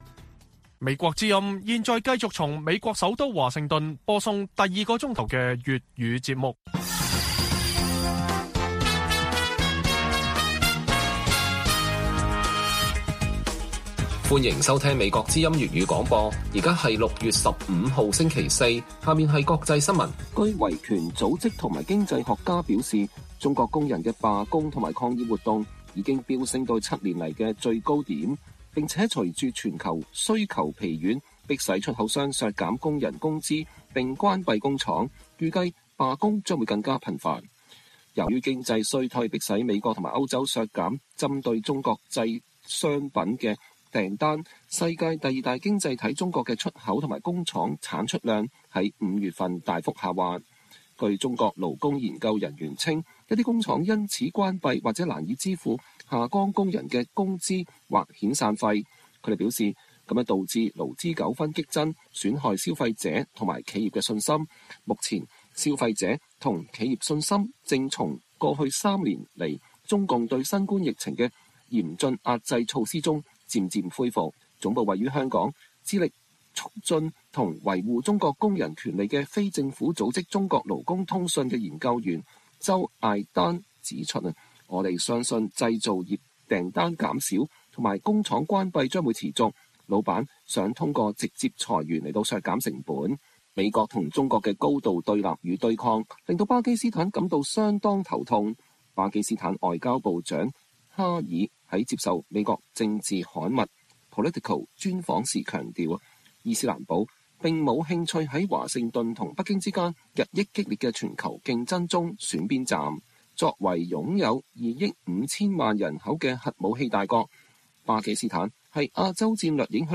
粵語新聞 晚上10-11點: 中國出口疲軟， 罷工抗議更趨頻繁